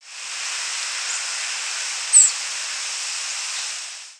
hypothetical Saltmarsh Sharp-tailed Sparrow nocturnal flight call